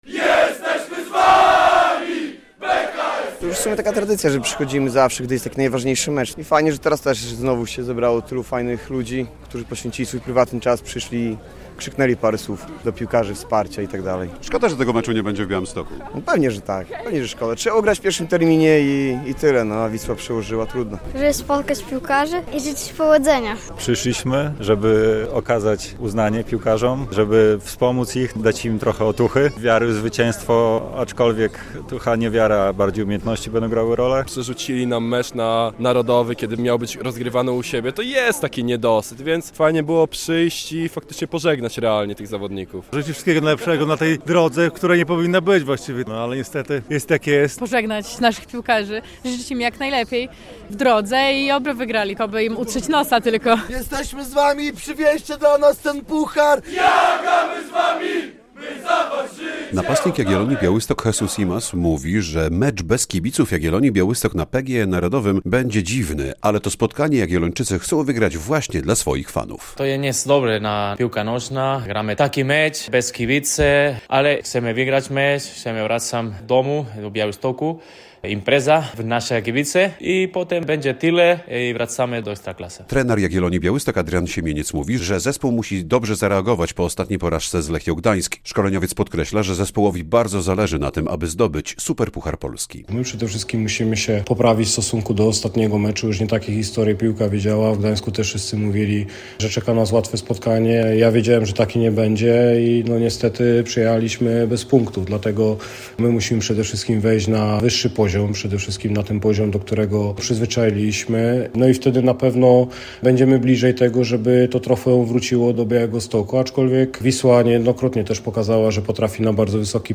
Kibice żółto-czerwonych, którzy przyszli na parking bazy treningowej przy ul. Elewatorskiej mówią, że trzymają kciuki za swoich ulubieńców.